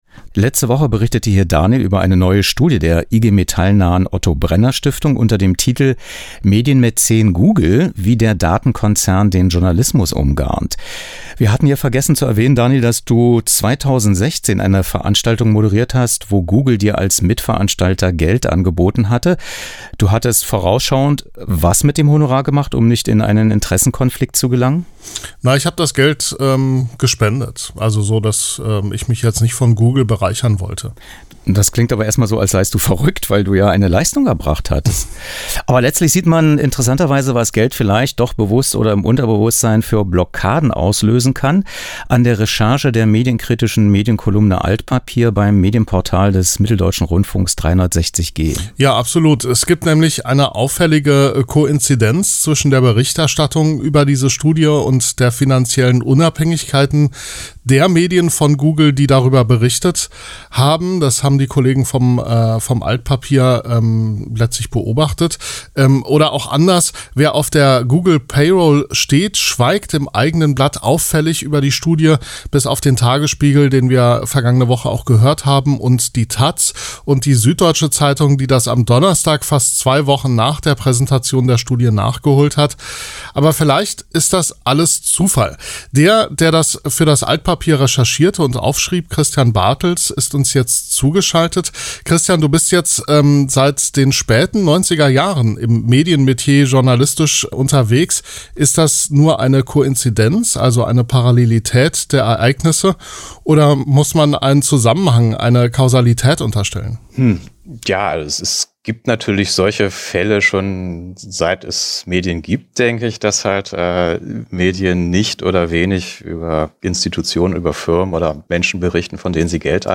Interview zum 20. „Altpapier“-Geburtstag
muPRO-Schaltgespräch